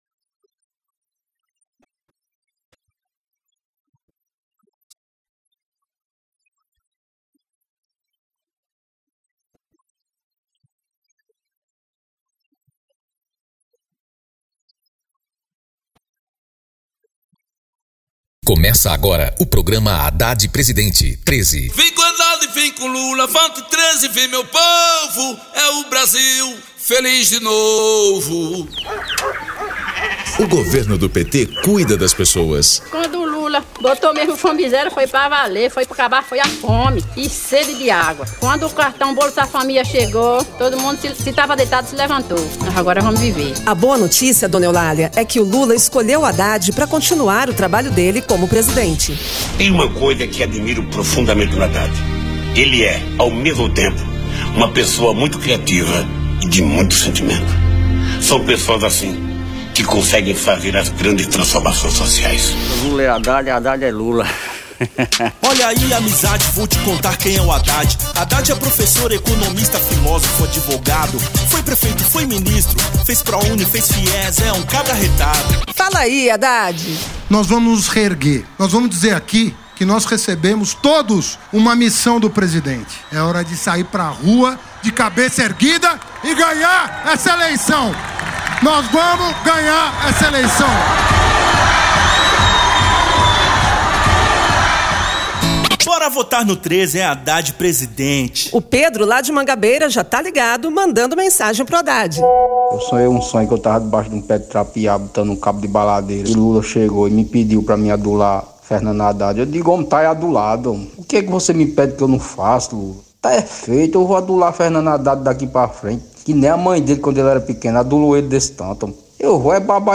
Descrição Programa de rádio da campanha de 2018 (edição 14) - 1° turno